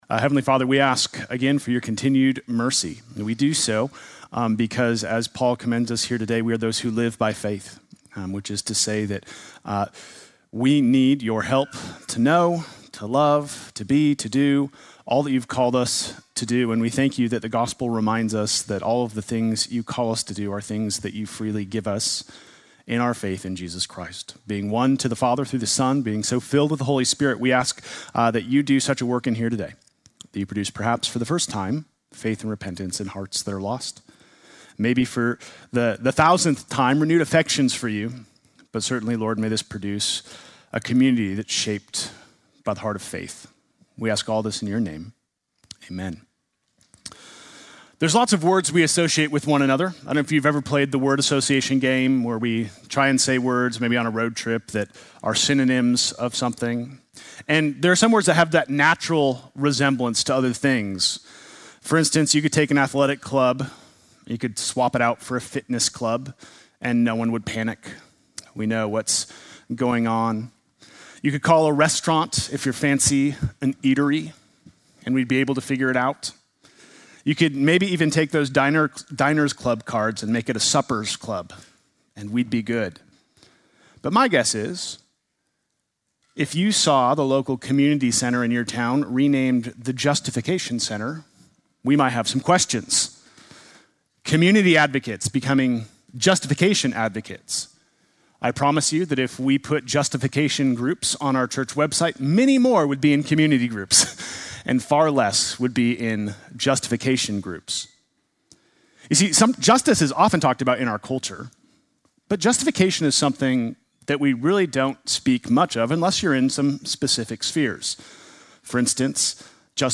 Sunday morning message November 16